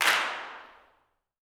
CLAPS 01.wav